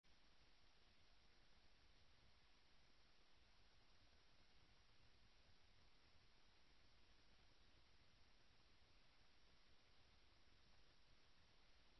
Avonddienst 26 april 2026